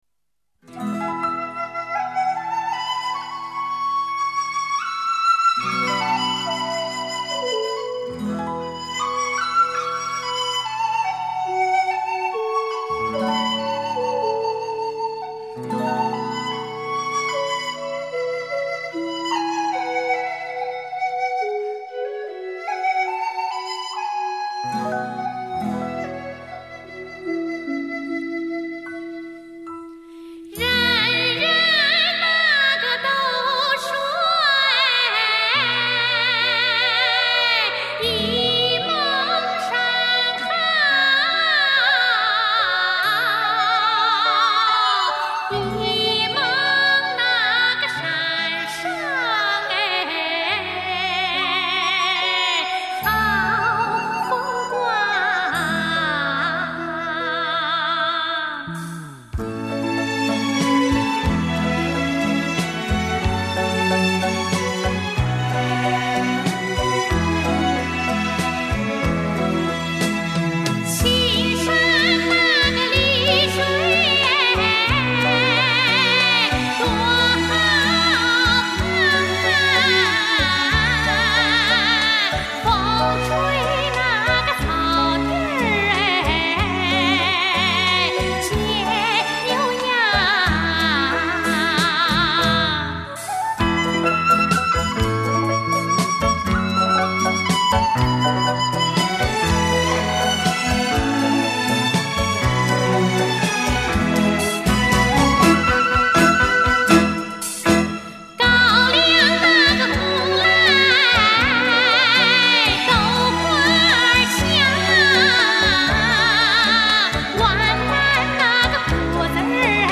很有韵味的古筝曲子，欣赏了